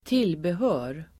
Uttal: [²t'il:behö:r]